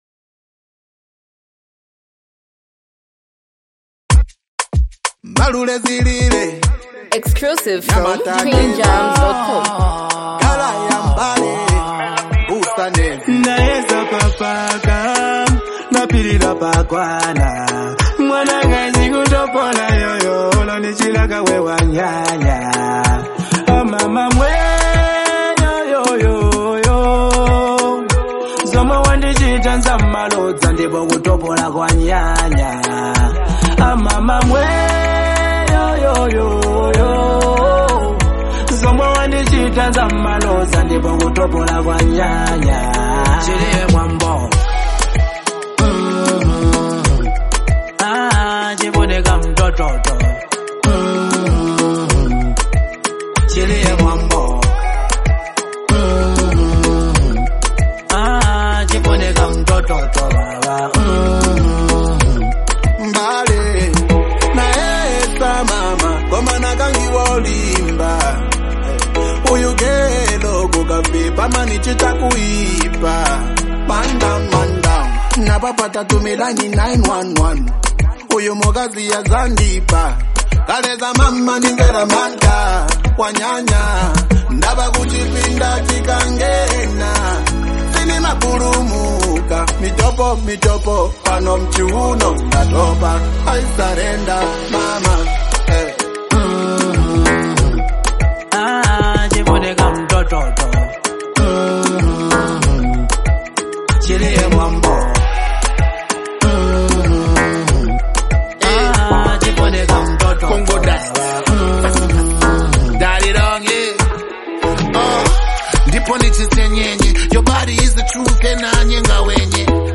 a culturally rich and emotionally grounded song
soulful vocals